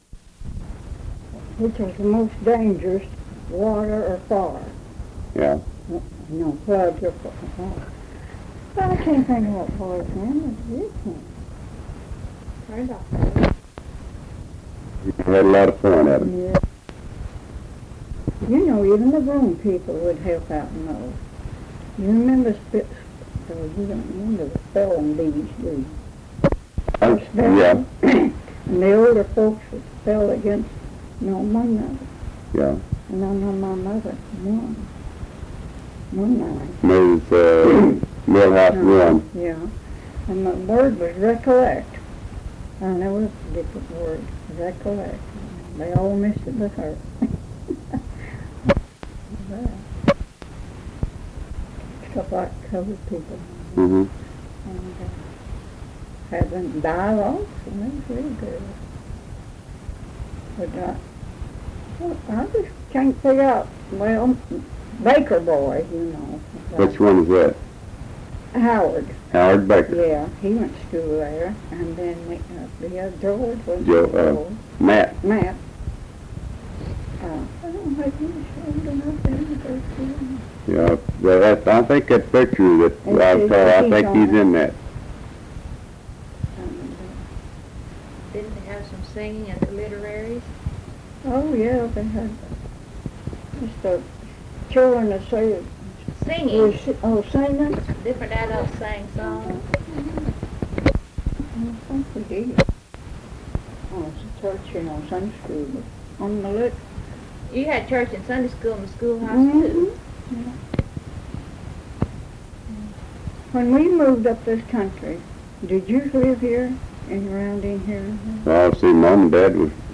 Oral History Archive | Pinehill Community